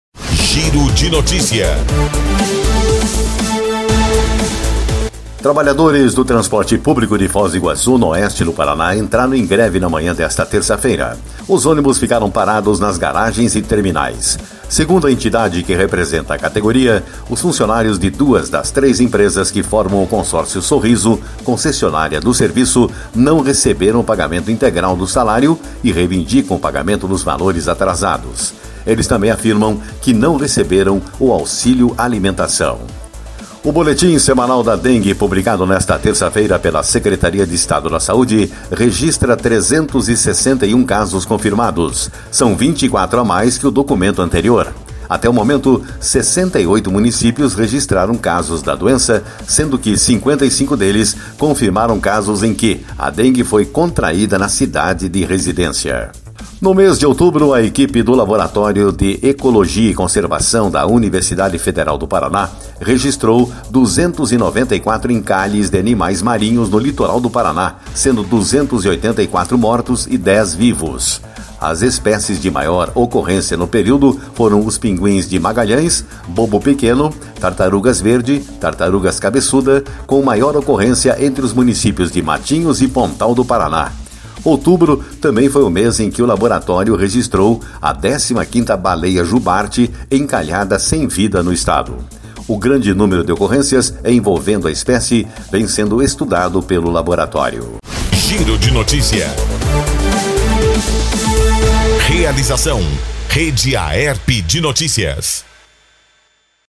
Giro de Notícias